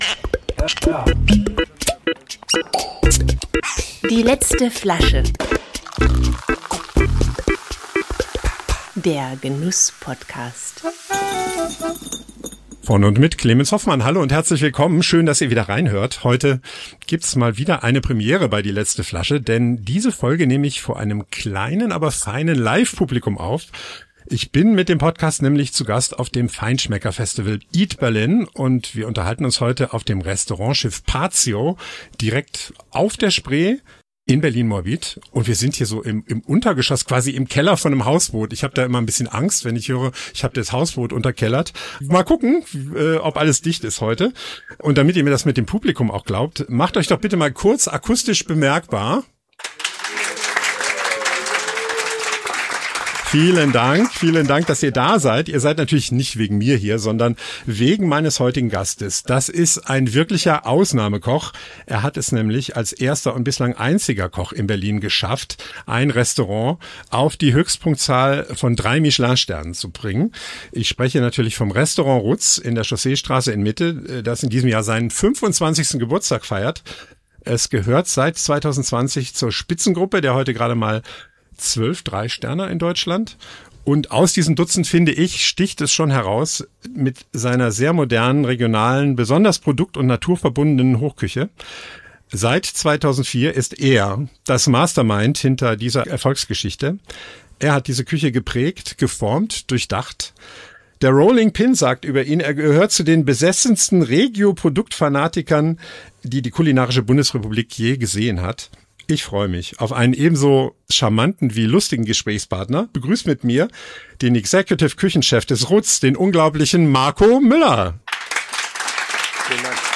Die letzte Flasche" live auf dem Feinschmeckerfestival Eat!Berlin